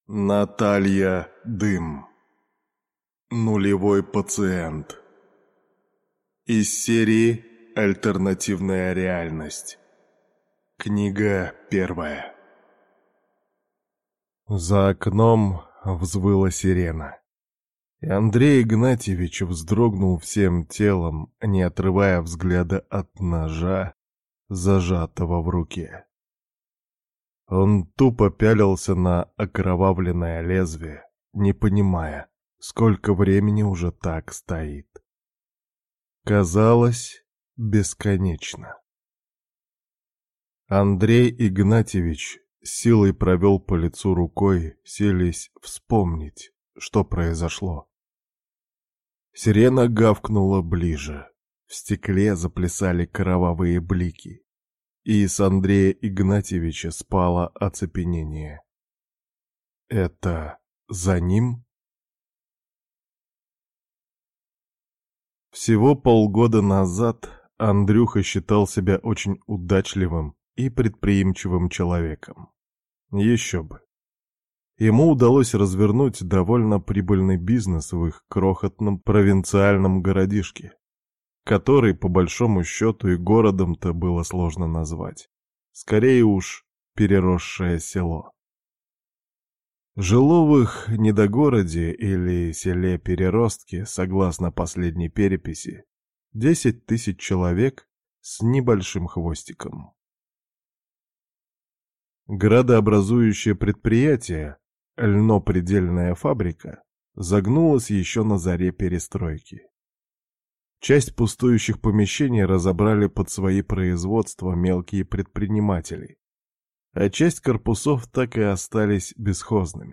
Аудиокнига Нулевой пациент | Библиотека аудиокниг